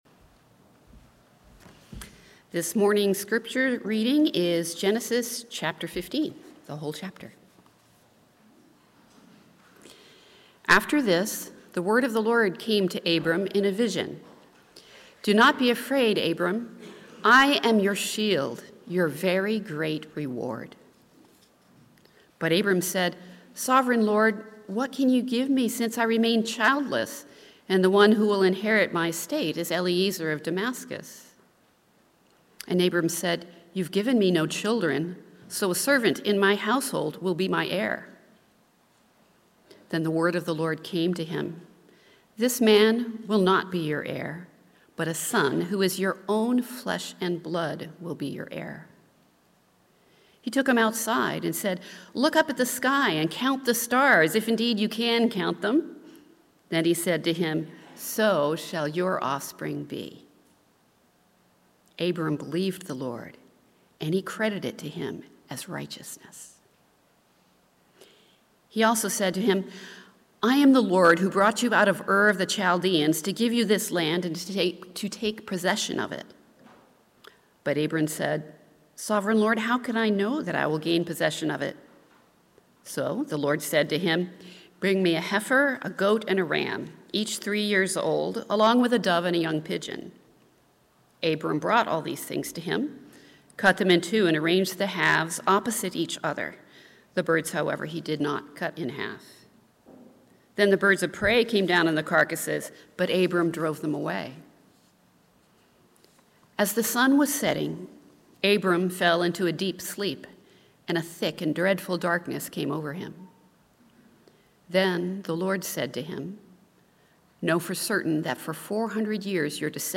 Sermons – North Shore Community Baptist Church